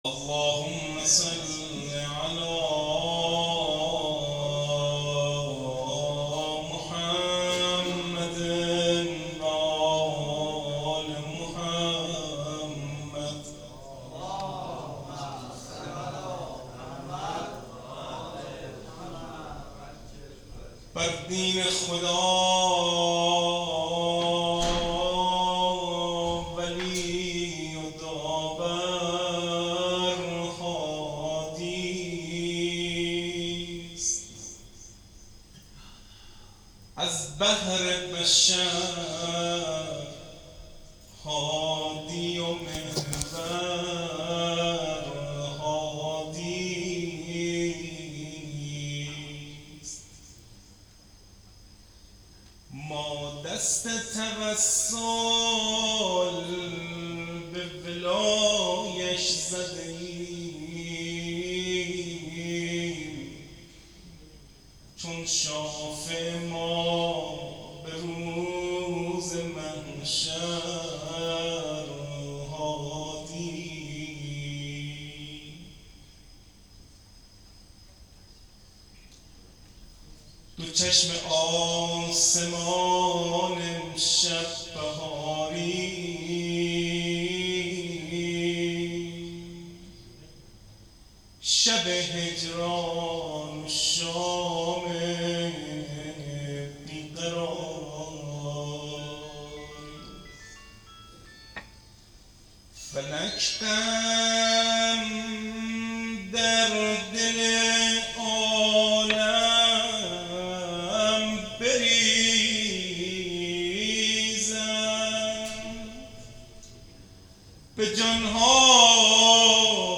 روضه امام هادی علیه السلام